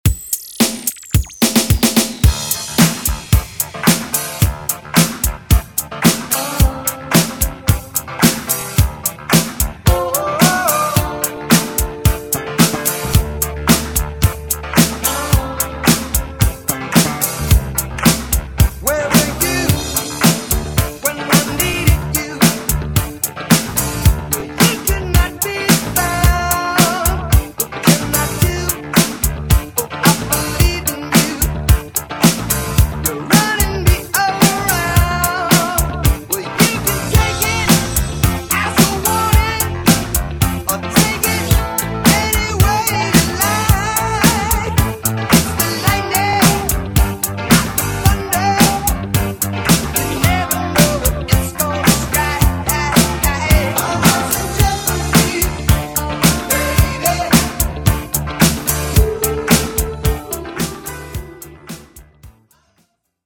guaracha